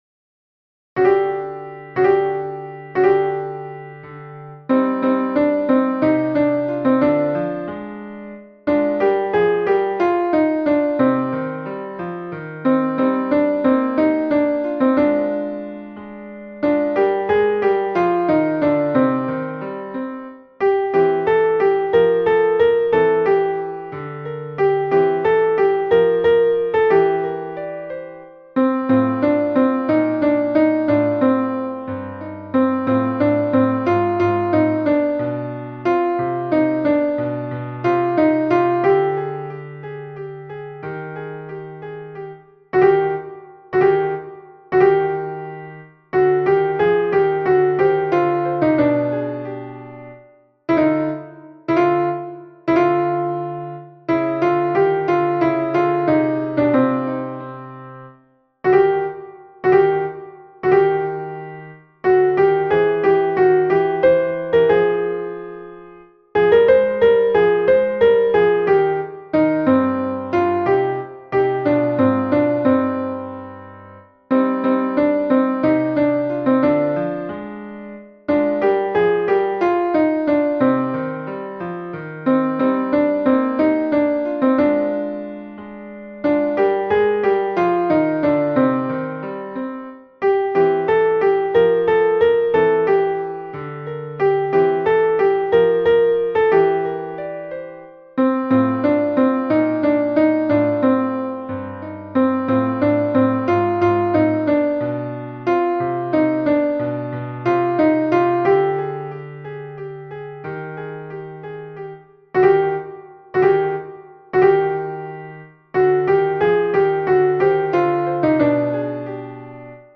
MP3 version piano
Padam padam alto (piano)
padam-padam-alto.mp3